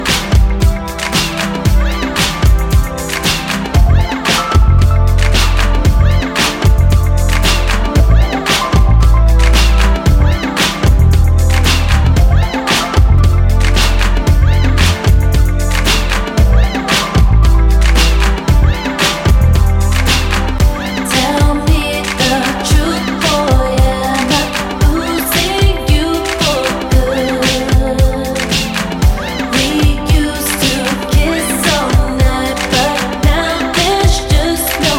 Жанр: Рок / Электроника / Дабстеп